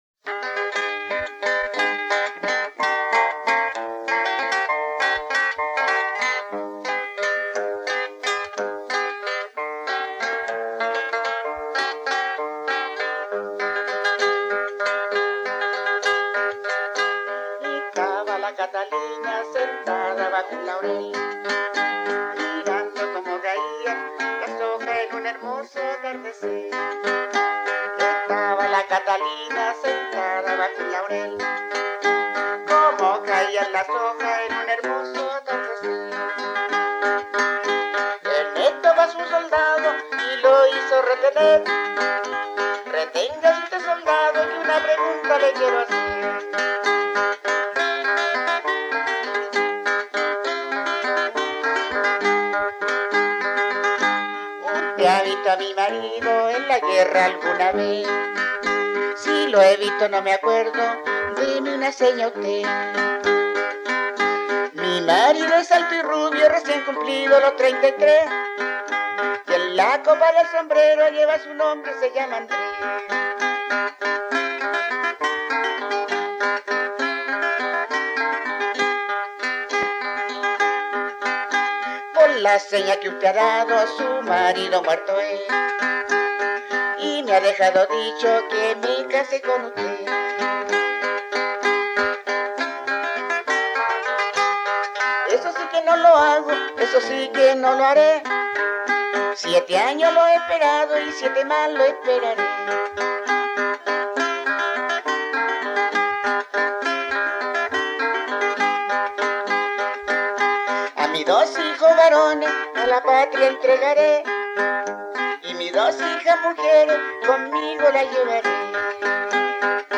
Romance en forma de vals que trata el tema tradicional de "las señas del marido".
Música tradicional
Folklore
Vals